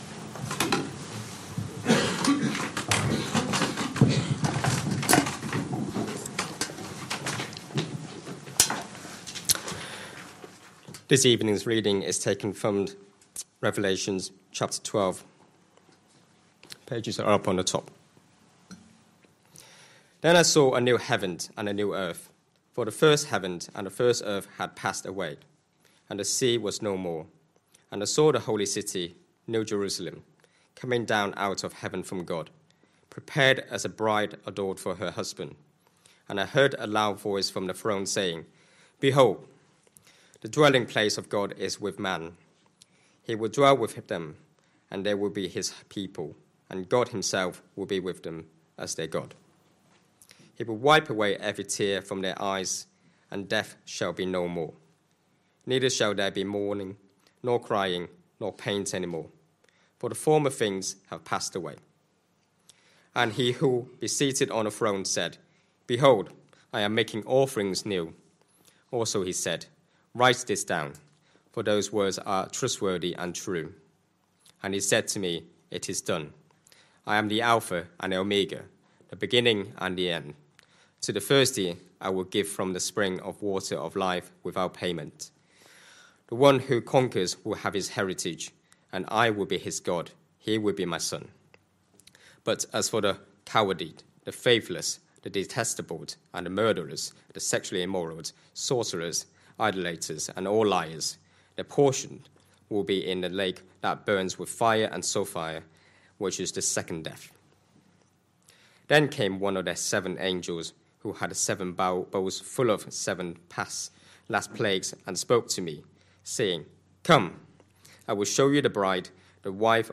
Christ Church Sermon Archive
Sunday PM Service Sunday 30th November 2025 Speaker